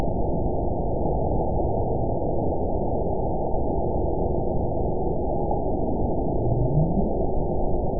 event 912441 date 03/27/22 time 00:07:50 GMT (3 years, 1 month ago) score 9.64 location TSS-AB03 detected by nrw target species NRW annotations +NRW Spectrogram: Frequency (kHz) vs. Time (s) audio not available .wav